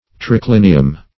Triclinium \Tri*clin"i*um\, n.; pl.